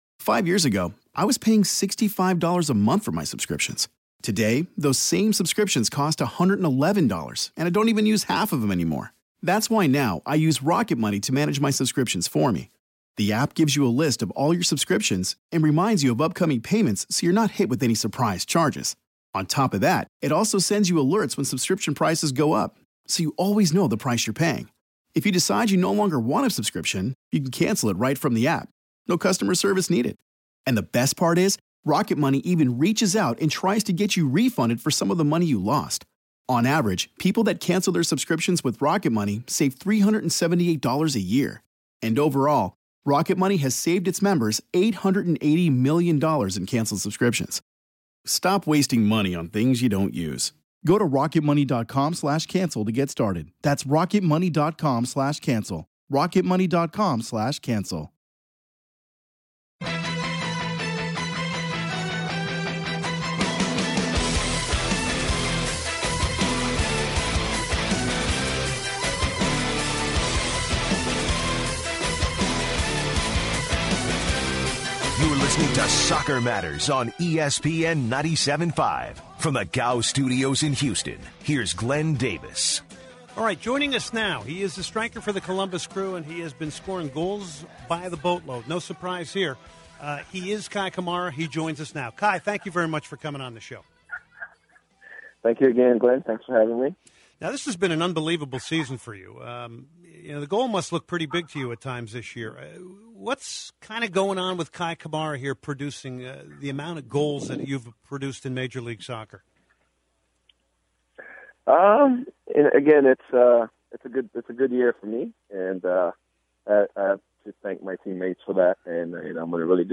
Kei Kamara, Columbus Crew striker, joins the show to talk: his feelings regarding being the top MLS scorer, his relationship with fellow goal scorers, his experiences in the MLS, how he connects with fans, and much more.